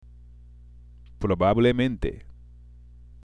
（プロバブレメンテ）